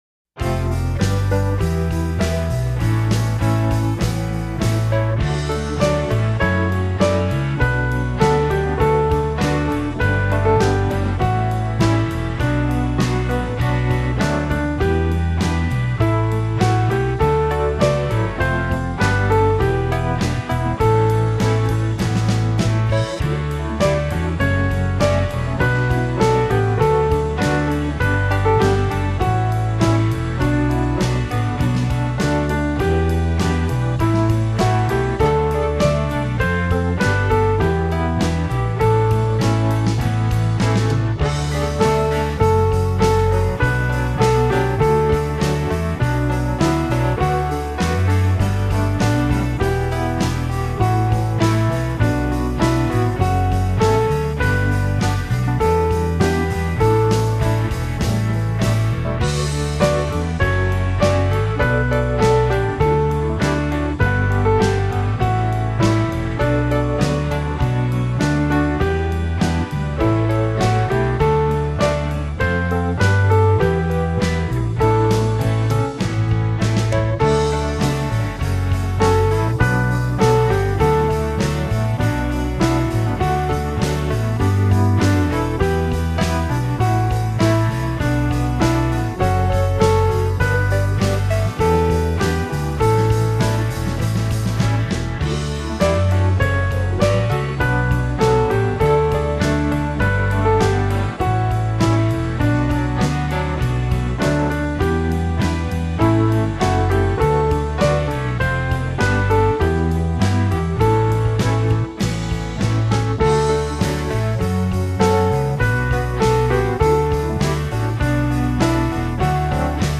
I love the twists in the tune and the 4/4 to 6/4 stumbles.
My backing is in a rock style but I think the tune survives.